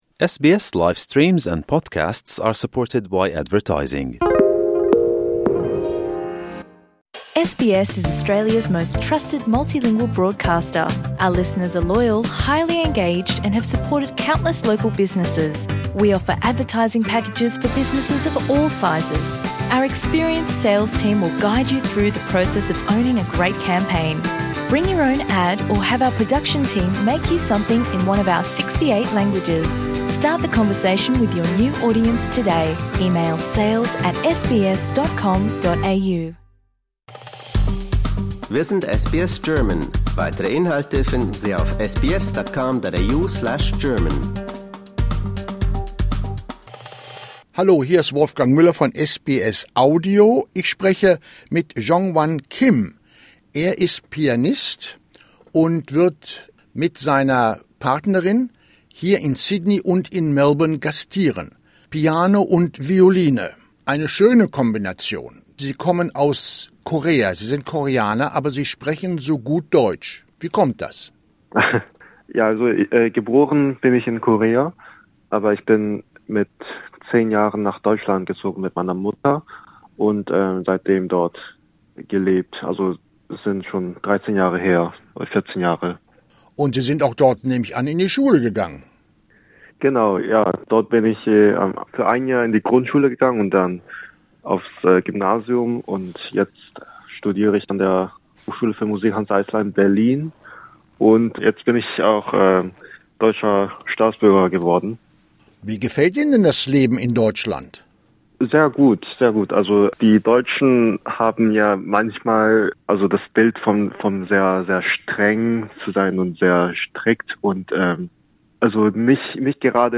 In zwei Teilen bzw. Räumen werden Arbeiten aus der Schaffensphase vor dem zweiten Weltkrieg unter dem Titel "Liebe und Verrat" zusammengefasst. Hier hört ihr einen Zusammenschnitt des ersten Rundgangs.